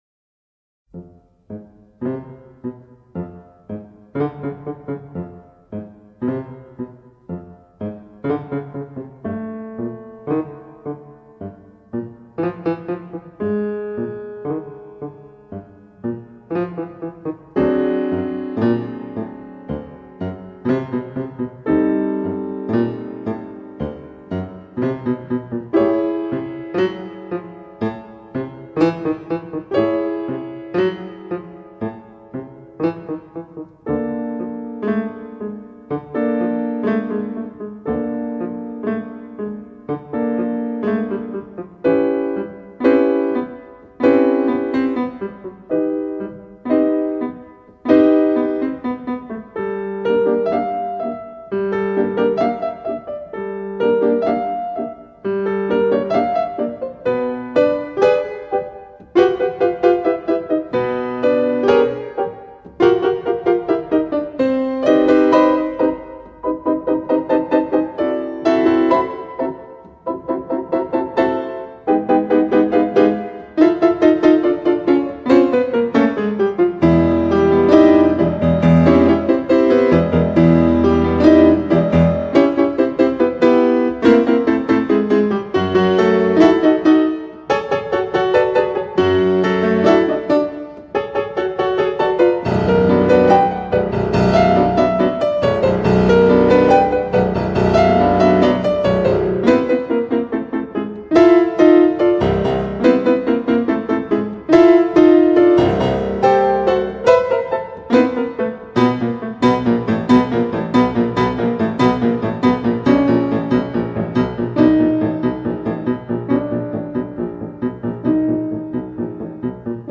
Tango rítmico y temperamental
piano